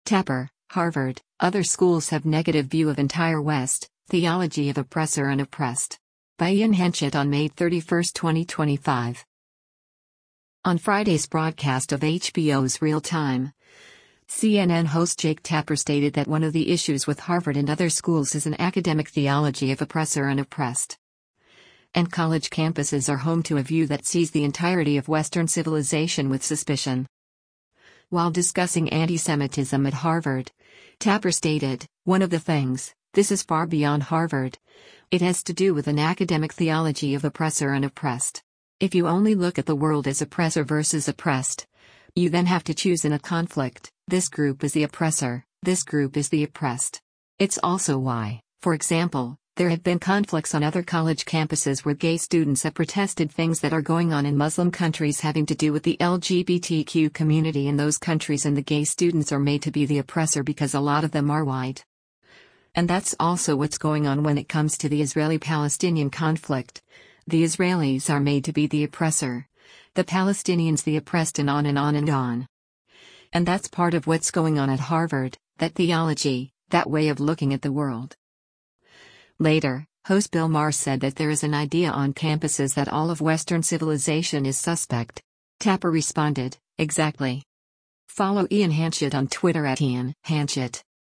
On Friday’s broadcast of HBO’s “Real Time,” CNN host Jake Tapper stated that one of the issues with Harvard and other schools is “an academic theology of oppressor and oppressed.”
Later, host Bill Maher said that there is an idea on campuses that “all of Western Civilization is suspect.”